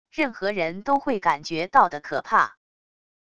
任何人都会感觉到的可怕wav音频